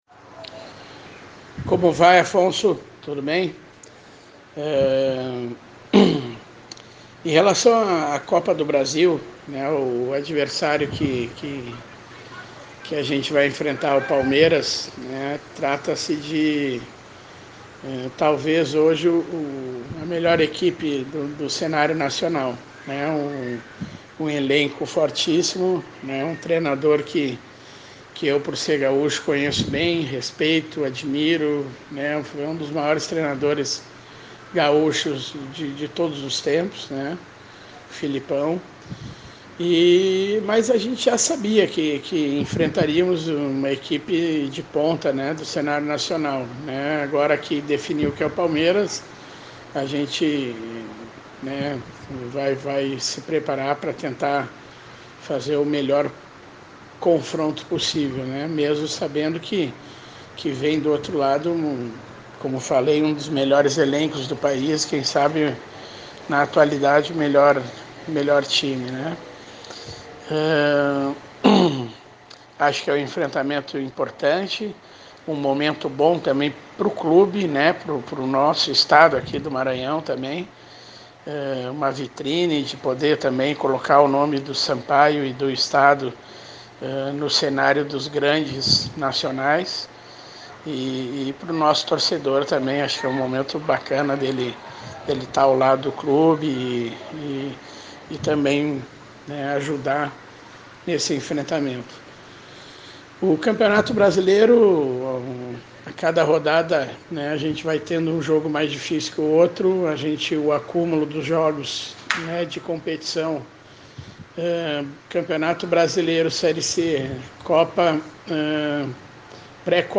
Entrevistão em áudio: Palmeiras e Bota-PB